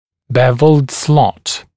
[‘bevld slɔt][‘бэвлд слот]паз (брекета) со скошенными кромками